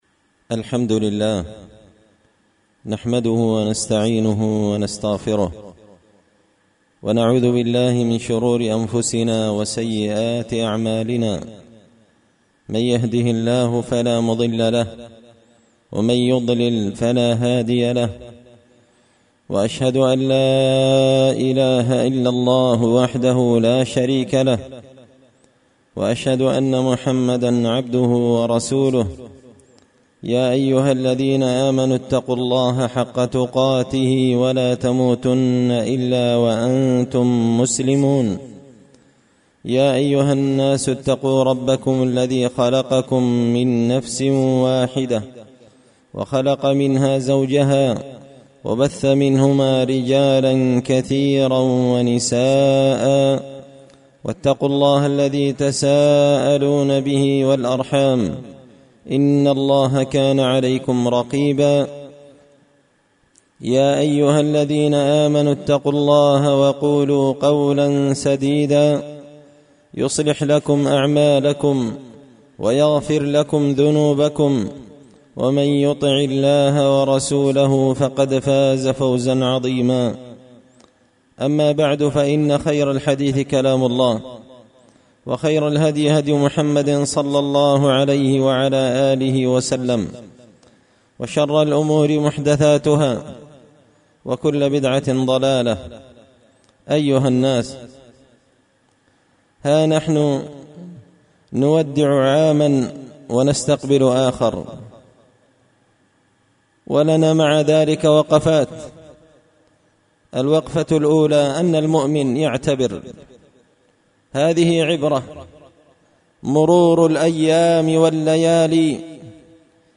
خطبة جمعة بعنوان – التنبيهات السديدة مع قدوم السنة الجديدة
دار الحديث بمسجد الفرقان ـ قشن ـ المهرة ـ اليمن